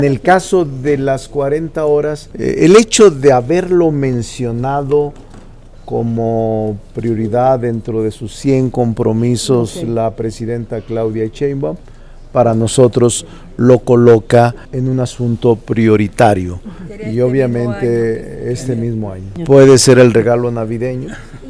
Entrevistado en el Palacio de San Lázaro, el también presidente de la Junta de Coordinación Política (JUCOPO) añadió que se empezará a trabajar sobre el dictamen aprobados en comisiones el año pasado, y que quedó congelado en la Mesa Directiva sin desahogarse.